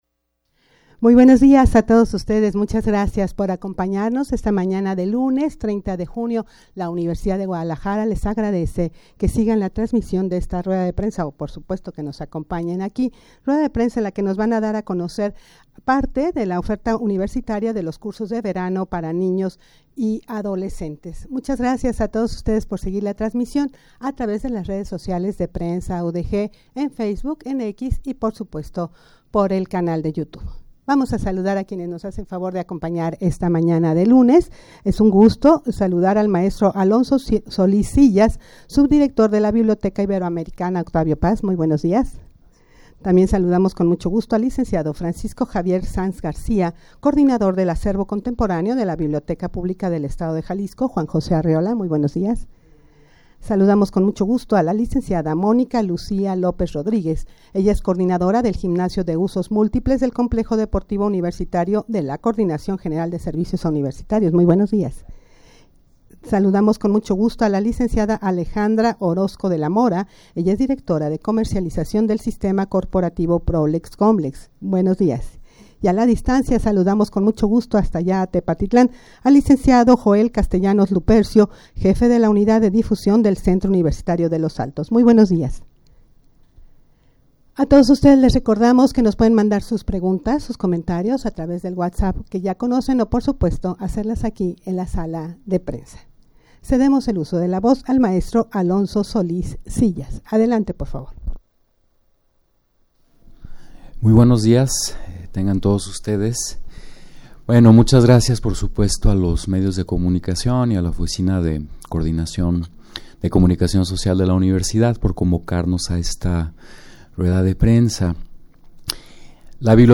Audio de la Rueda de Prensa
rueda-de-prensa-para-dar-a-conocer-oferta-universitaria-de-cursos-de-verano-para-ninos-y-adolescentes.mp3